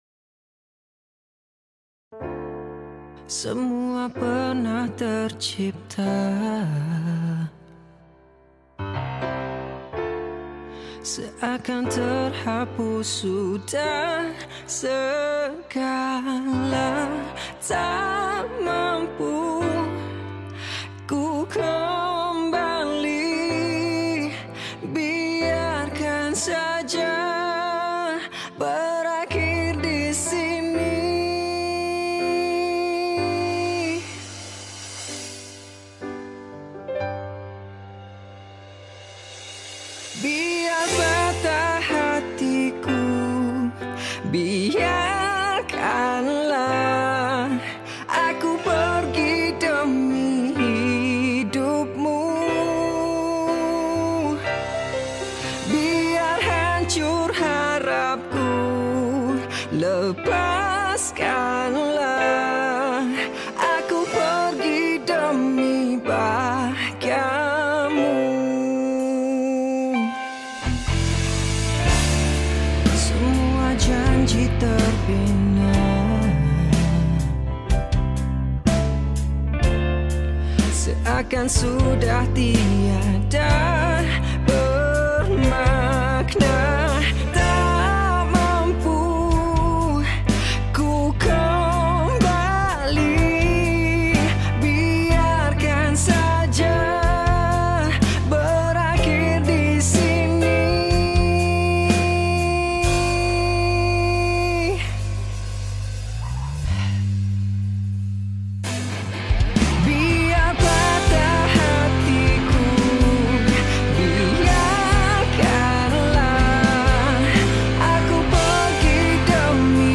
penyanyi pop